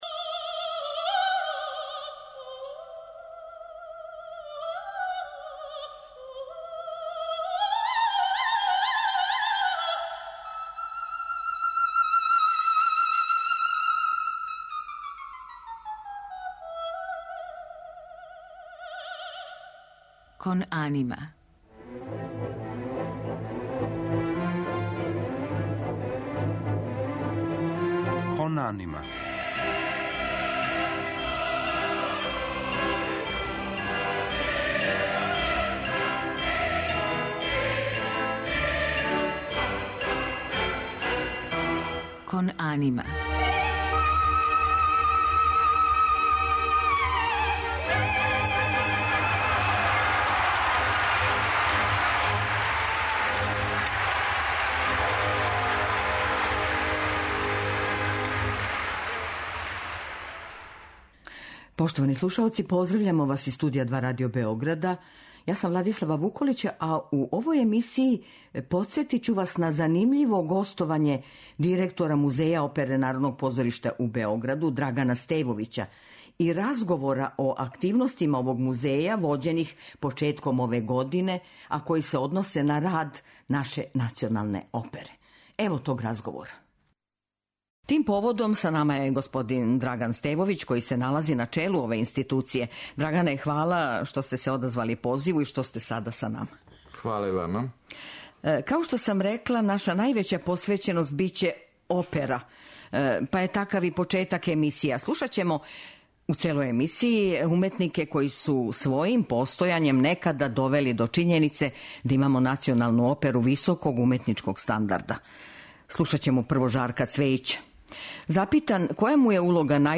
мецосопран